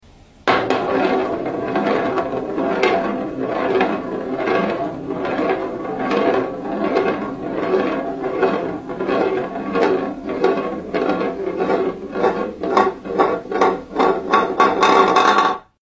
Marble Run One Blue Ball Sound Effects Free Download